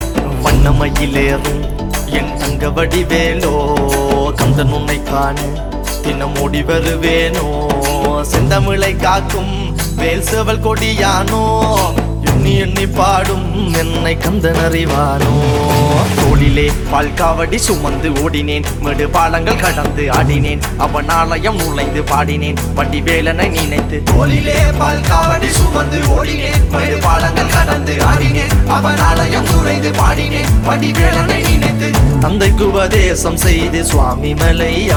best flute ringtone download | bhakti song ringtone